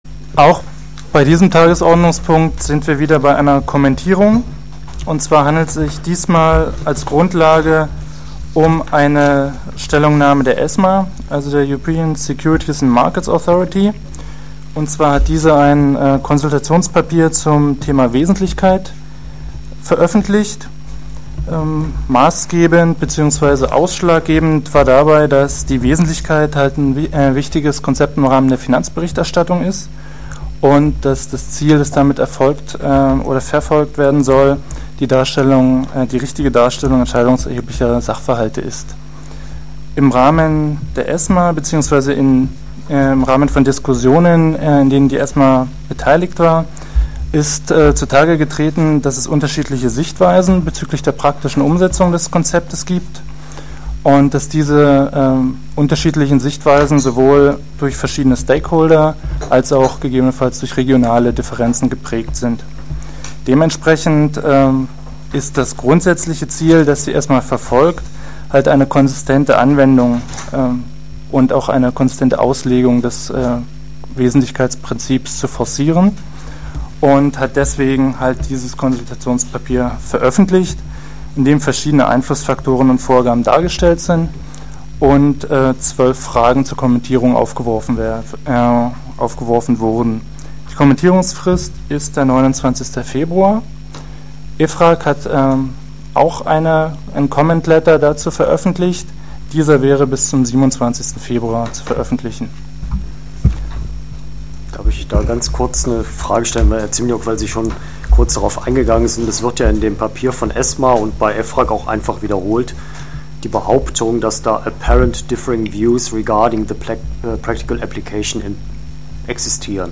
Im Rahmen der sich anschließenden Diskussion zwischen den beiden Gästen und den Mitgliedern des Fachausschusses wird die Zusammenarbeit der beiden Gremien erörtert.